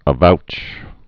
(ə-vouch)